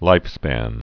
(līfspăn)